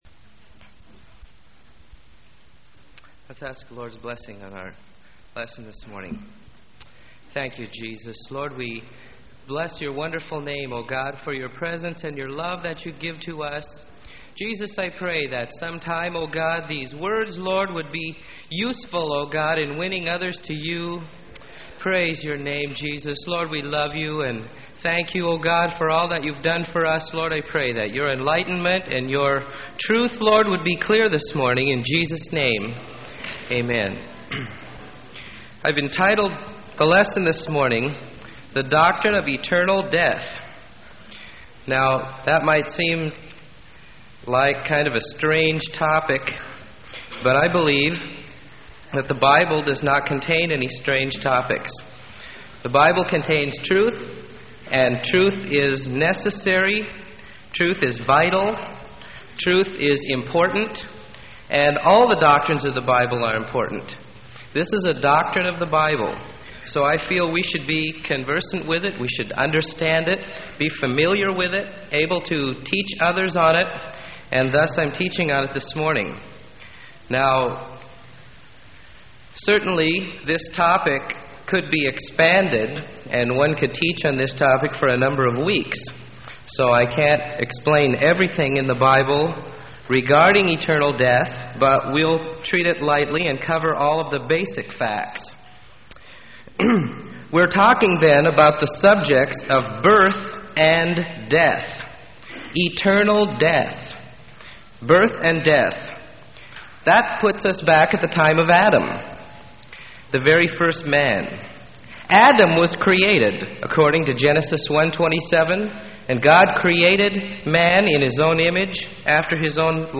Sermon: The Doctrine of Eternal Death - Freely Given Online Library